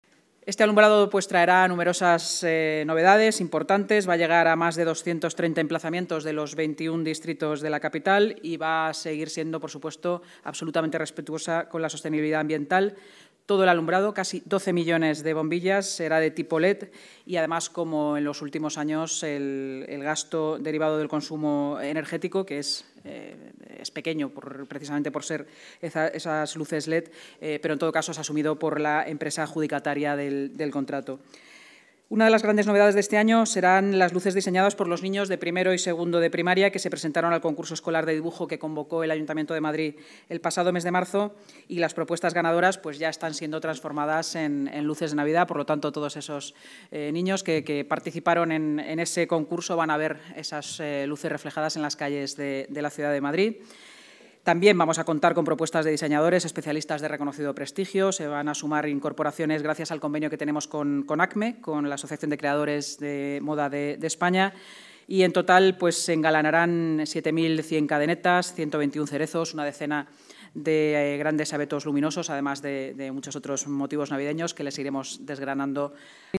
Así lo ha señalado esta mañana en rueda de prensa la vicealcaldesa y portavoz municipal, Inma Sanz, tras la reunión semanal de la Junta de Gobierno.